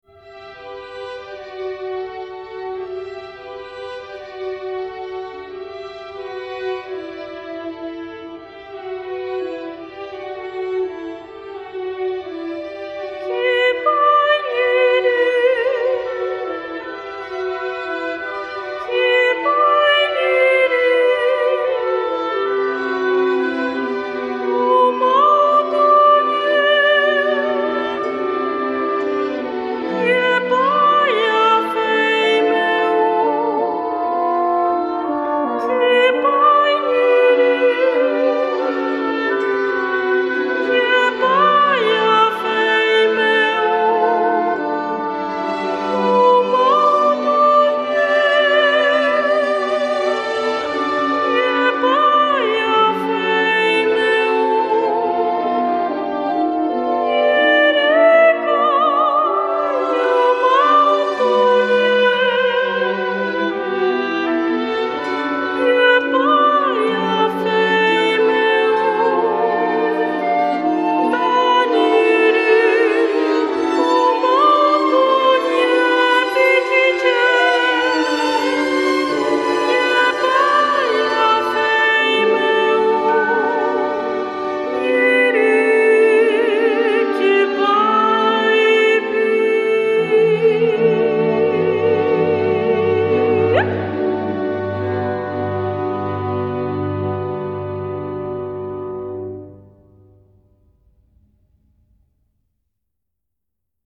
Orquesta
Música vocal